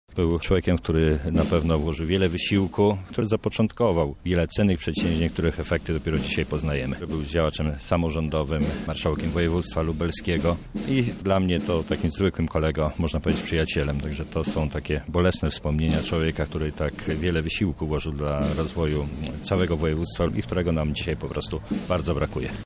O tym jak zapamiętał Edwarda Wojasa mówi Paweł Pikuła, starosta lubelski.